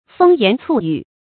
风言醋语 fēng yán cù yǔ 成语解释 散布嫉妒性的中伤语言。